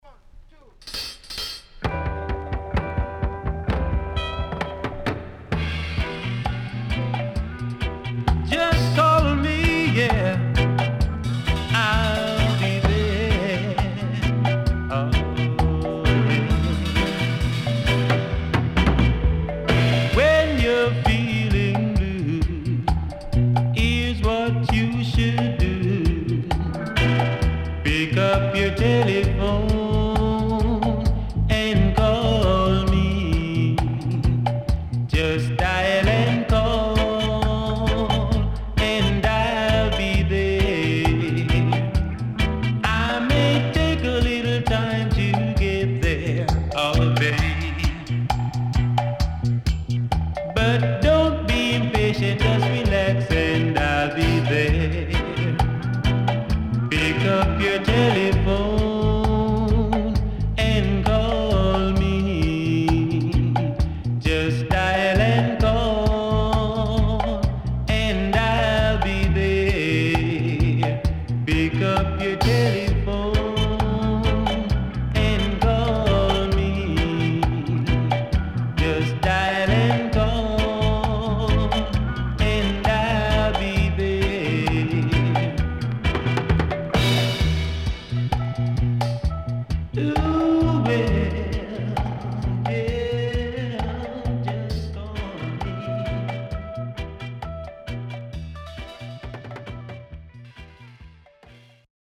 SIDE A:少しノイズ入ります。